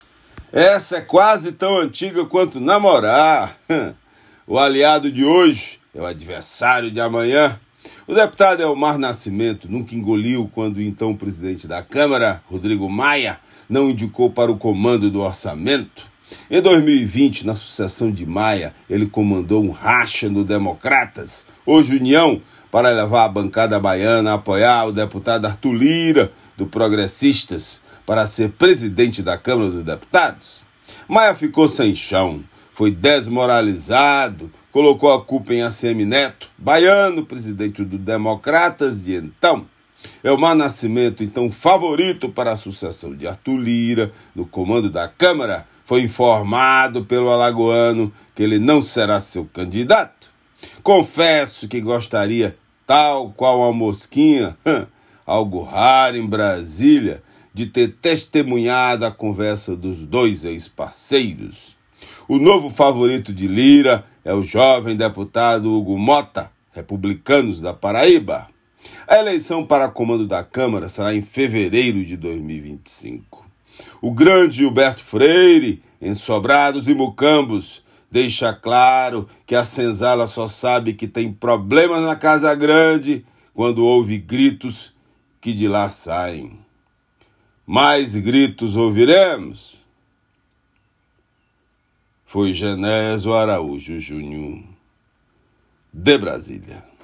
Comentário desta quinta-feira (05/09/24)